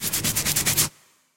scratch.ogg.mp3